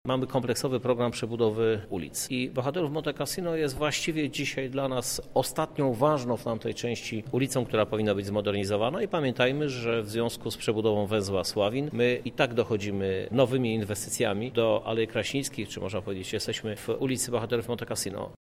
Dotacja 2 mln– dodaje prezydent miasta Krzysztof Żuk.